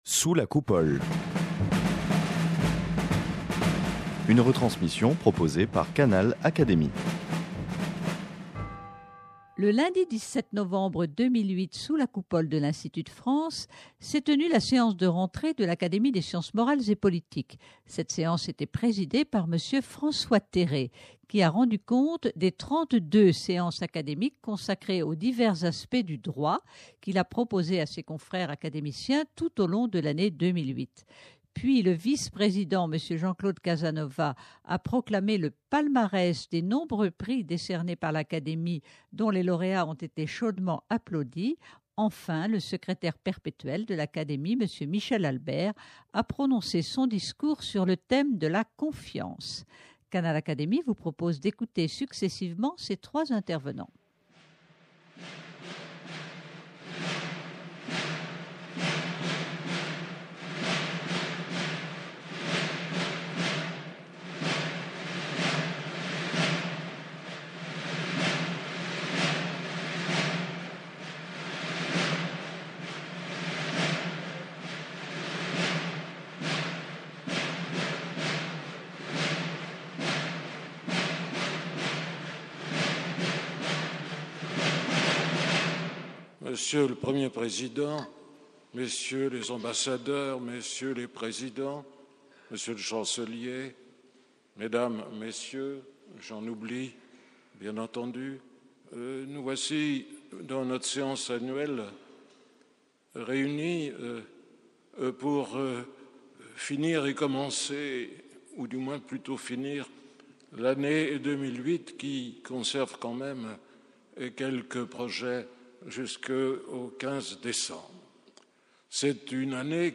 Ont successivement pris la parole, M. François Terré, président, M. Jean-Claude Casanova, qui a proclamé le palmarès des prix, et M. Michel Albert, Secrétaire perpétuel, dont le discours a pour thème la confiance.
Le lundi 17 novembre 2008, sous la Coupole de l'Institut de France, s'est tenue la séance de rentrée de l'Académie des sciences morales et politiques.
Puis le vice-président, M. Jean-Claude Casanova, a lu le palmarès des nombreux prix que l'Académie, conformément à sa mission, décerne chaque année. Les lauréats ont été chaudement applaudis.